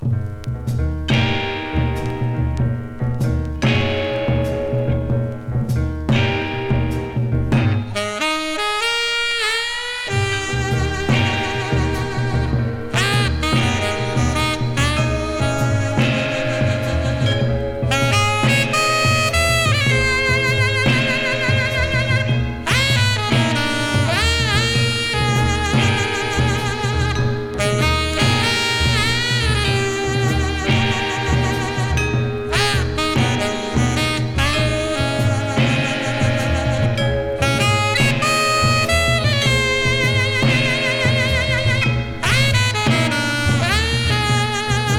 熱さも涼しも感じるような、洗練と洒落気たっぷり。サックスはもちろん、バック陣の演奏最高です。
Jazz, Rhythm & Blues　USA　12inchレコード　33rpm　Stereo